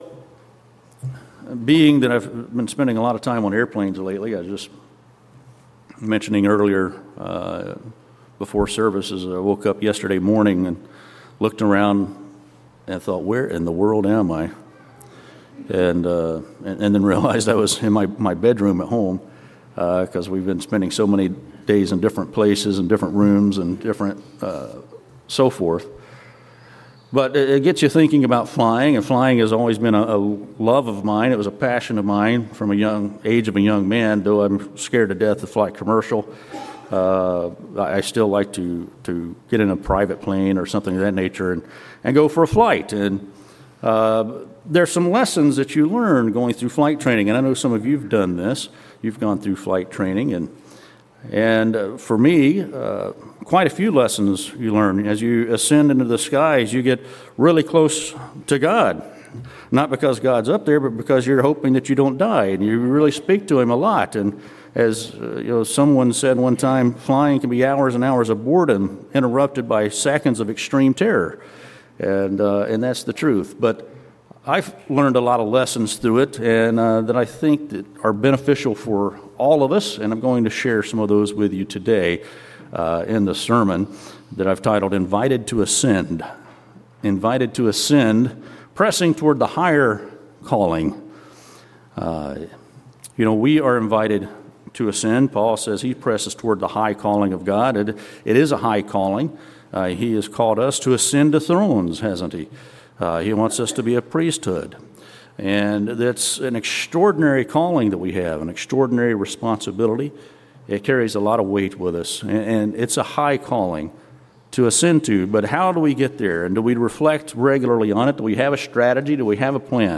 The sermon "Invited to Ascend" uses glider flight training as a metaphor for the Christian journey, emphasizing the need for proven faith, godly instruction, preparation, and community support. Believers are called to embrace spiritual turbulence and actively practice their faith in order to rise to the high calling of God.
Given in Jacksonville, FL